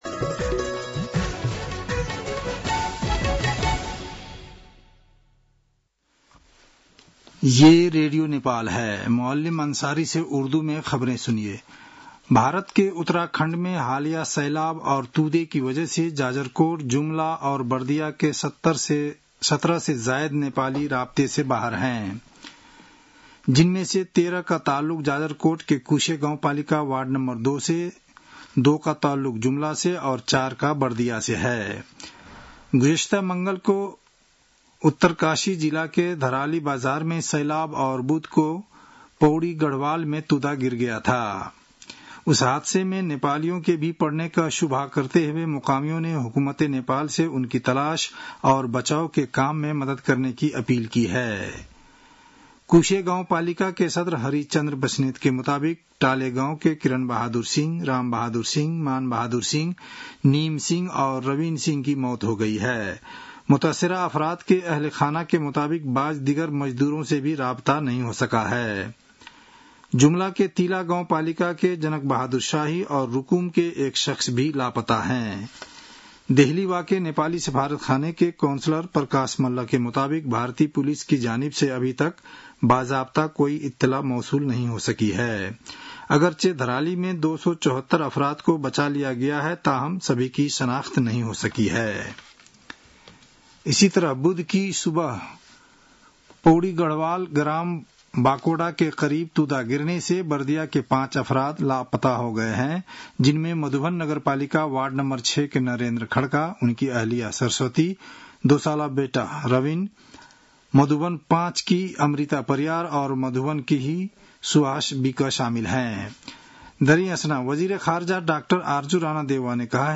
उर्दु भाषामा समाचार : २३ साउन , २०८२
Urdu-news-4-23.mp3